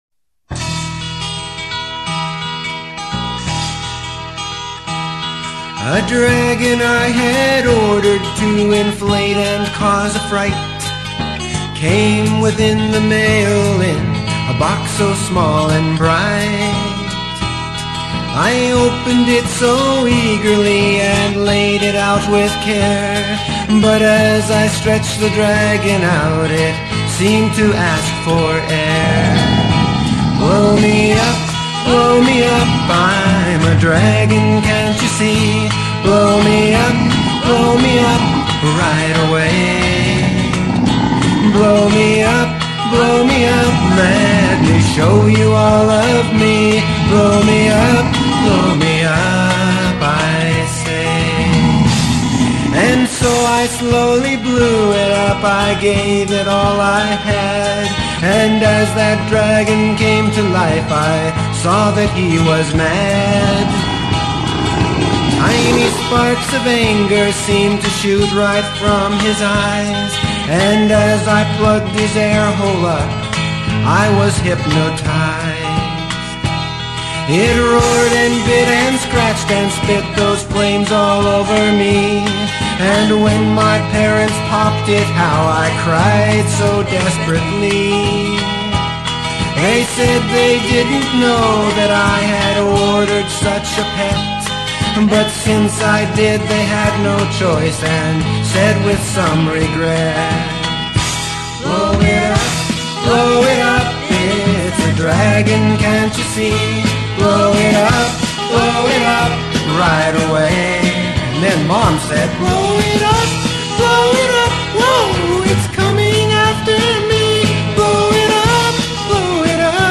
Below are some songs that have not been adequately produced.